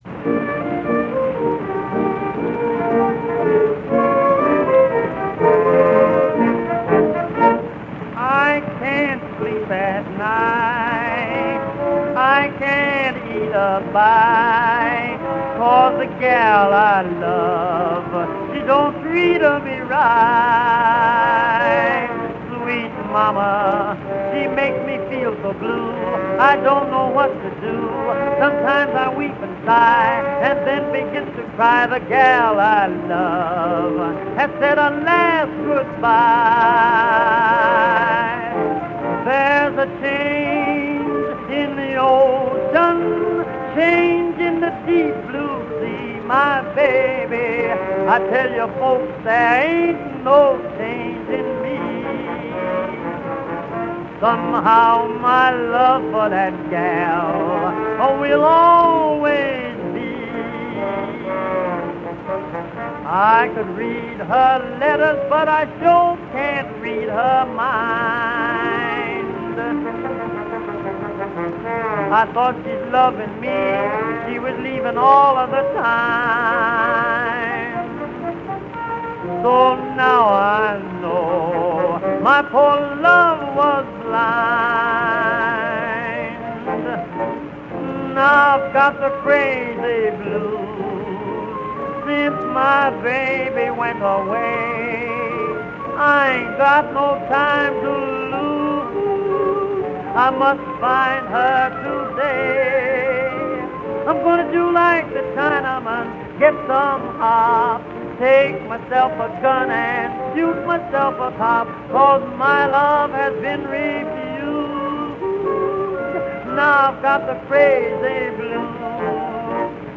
Sissle & Blake
So weird to hear a male vocalist tackle this track which was popularized by several of the early blues women who founded the Vaudeville Blues Style.